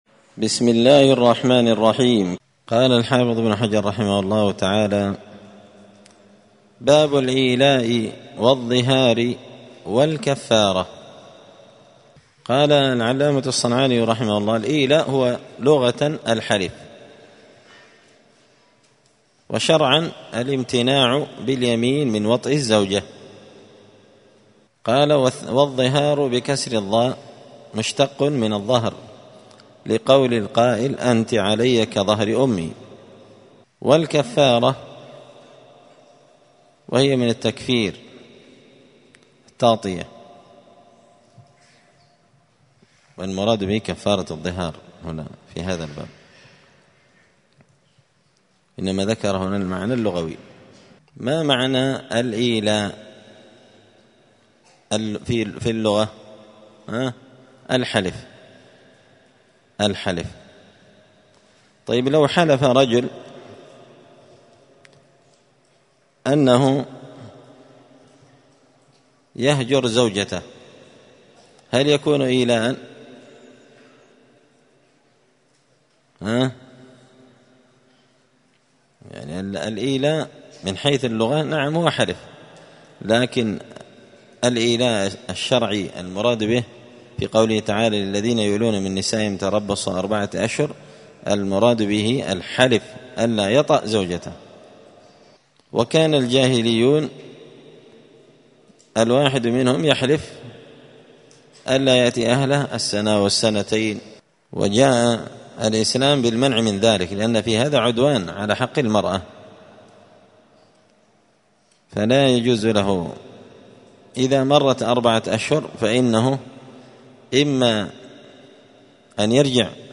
*الدرس العاشر (10) {باب الإيلاء والظهار والكفارة}*
دار الحديث السلفية بمسجد الفرقان بقشن المهرة اليمن